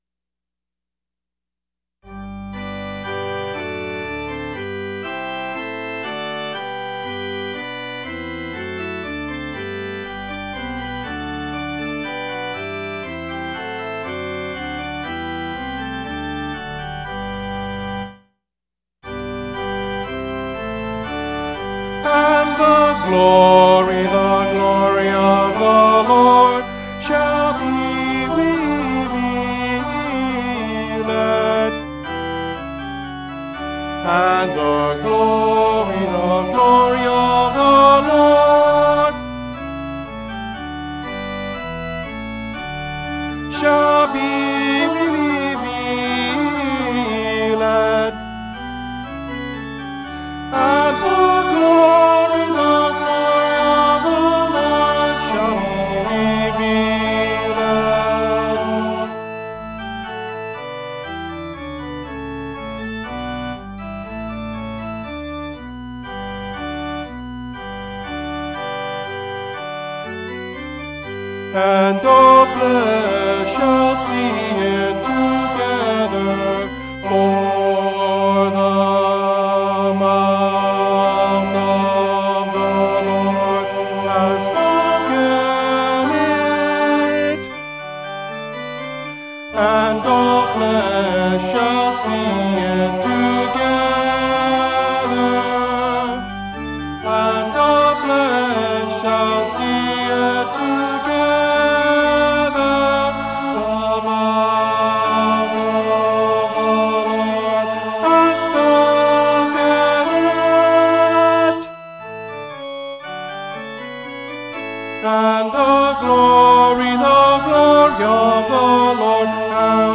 Key of G:
Tenor   Instrumental | Downloadable   Voice | Downloadable